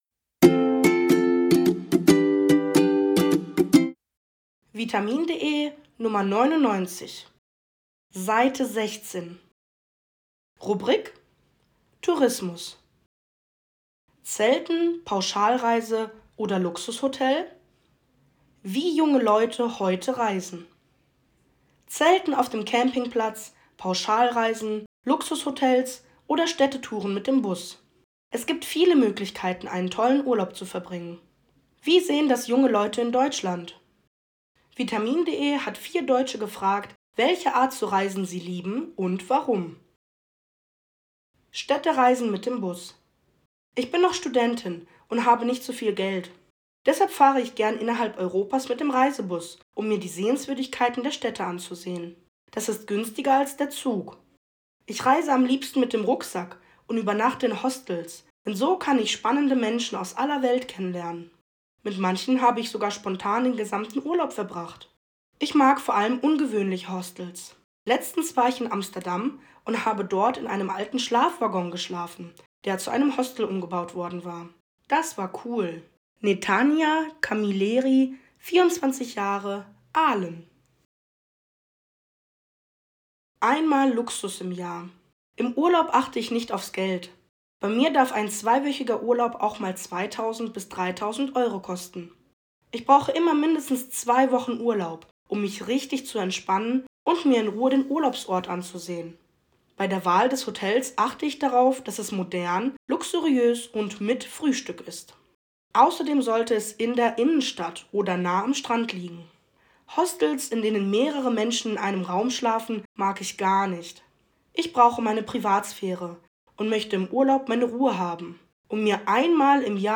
Audiodatei (Hörversion) zum Text